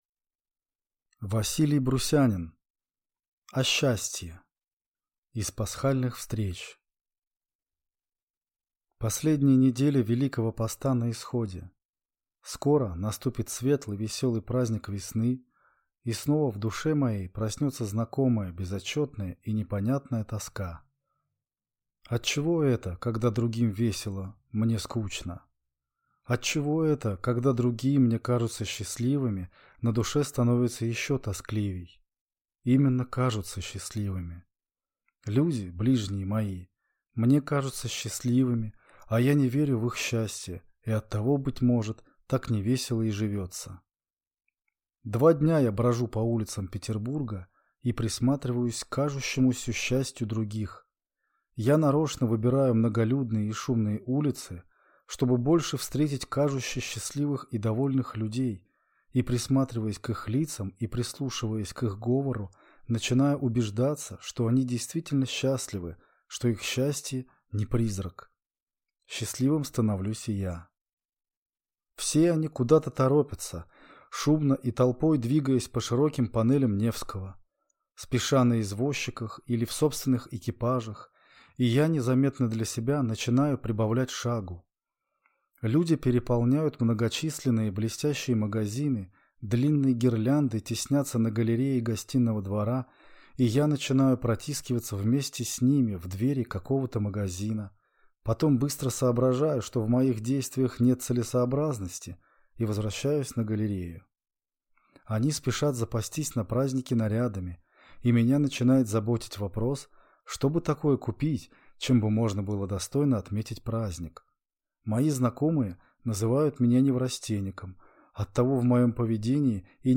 Читает аудиокнигу